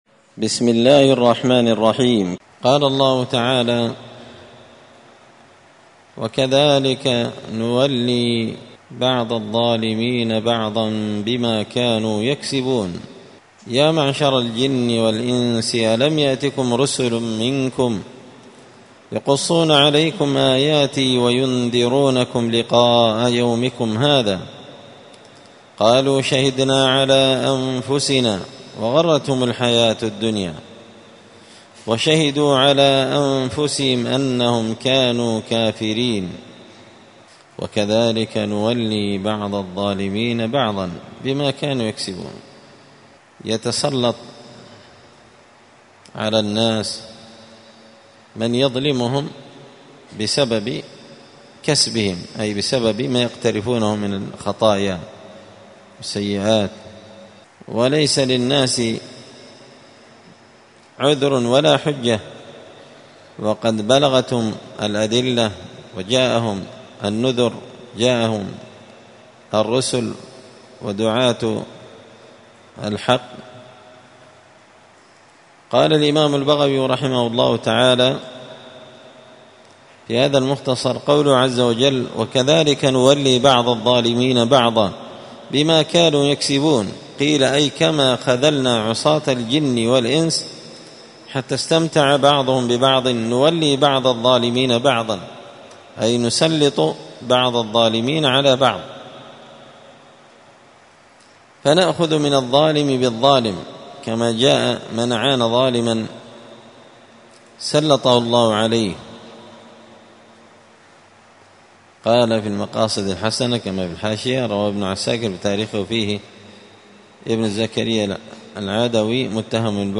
مختصر تفسير الإمام البغوي رحمه الله الدرس 355